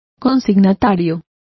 Complete with pronunciation of the translation of addressees.